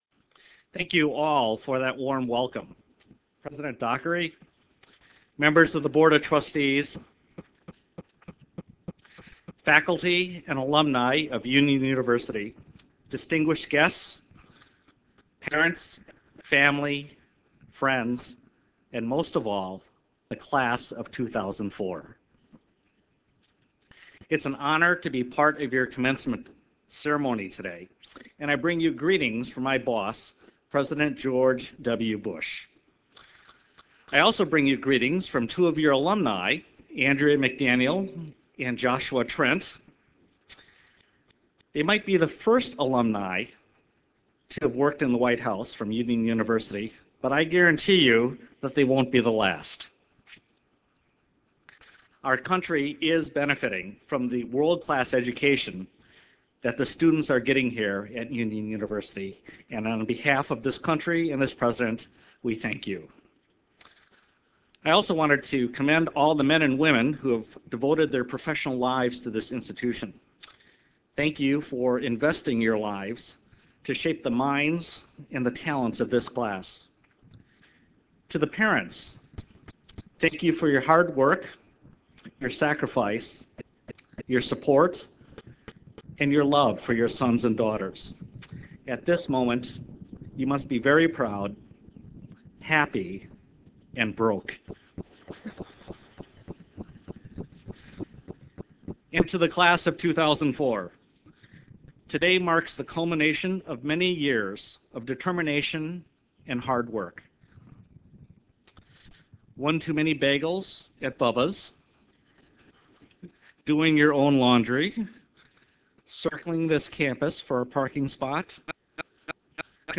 Spring 2004 Commencement: Commencement Address - Edmund C. Moy
MoyAddress.wma